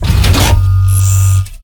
droidic sounds
attack1.ogg